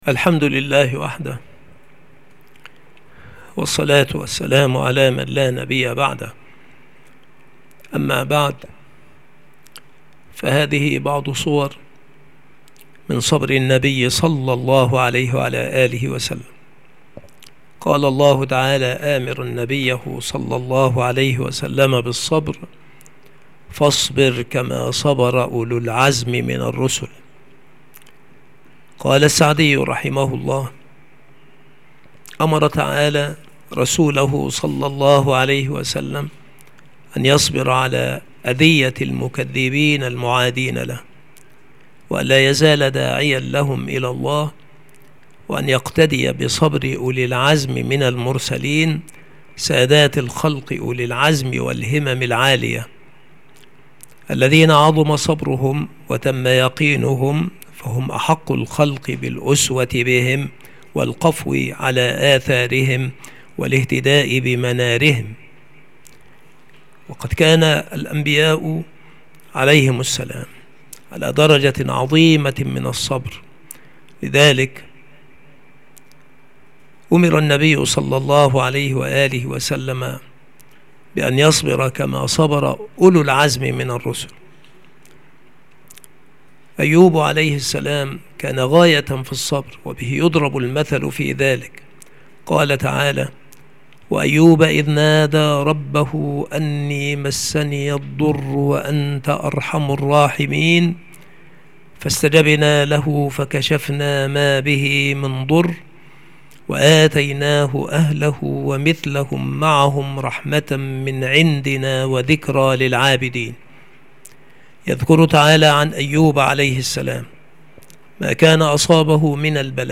مكان إلقاء هذه المحاضرة المكتبة - سبك الأحد - أشمون - محافظة المنوفية - مصر عناصر المحاضرة : صور من صبر النبي صلى الله عليه وسلم.